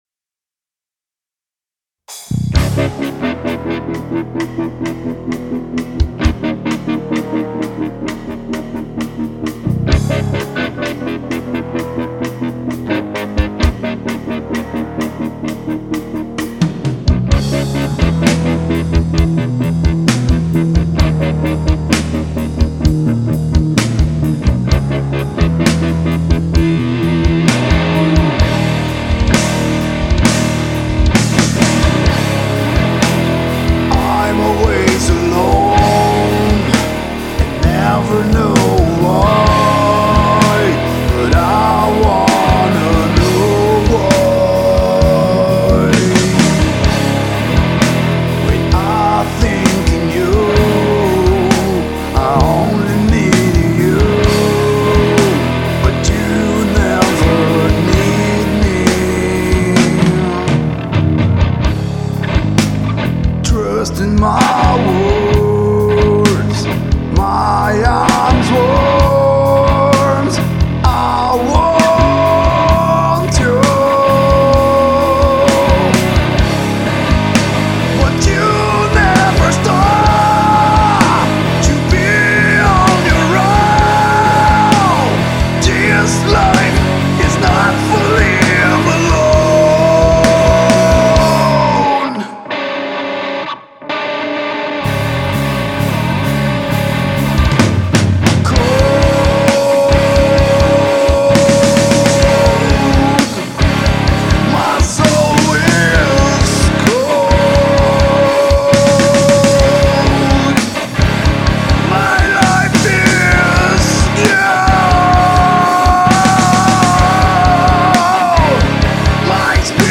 EstiloRock
A pegada é mais lenta